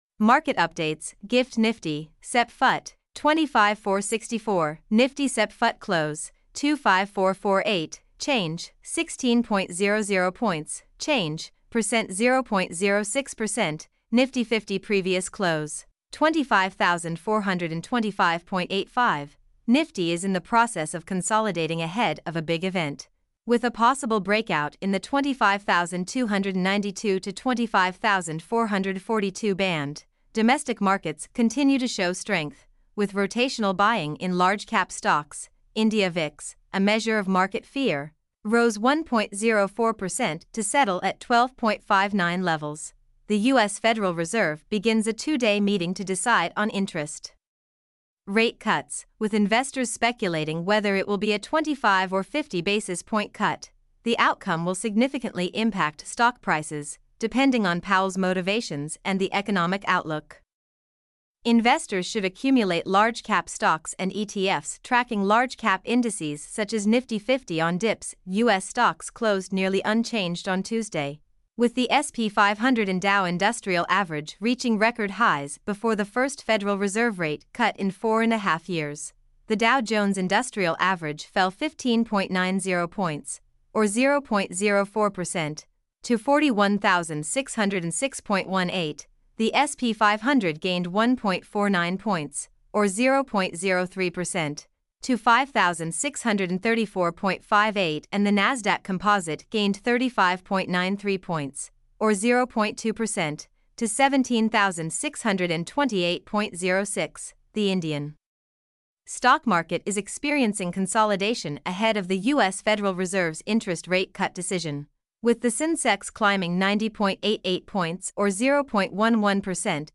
mp3-output-ttsfreedotcom-3.mp3